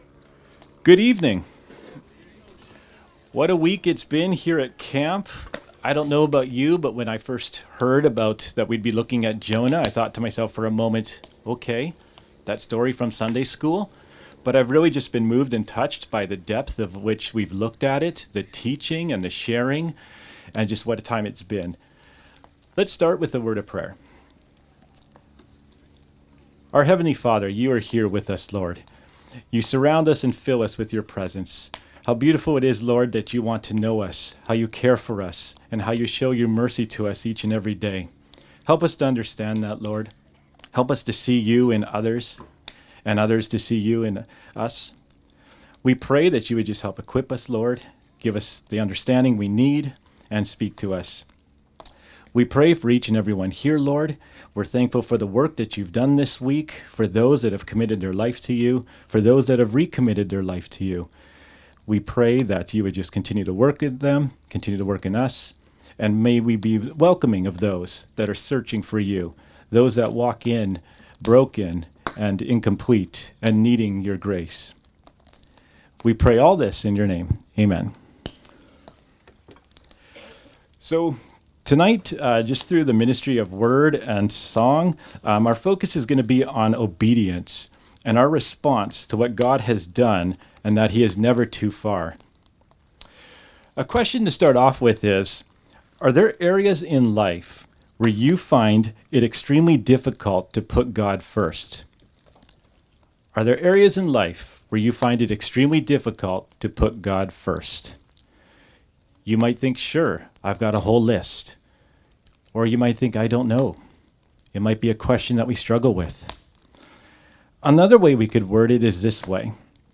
Saturday Campfire
Saturday-Campfire-2025.mp3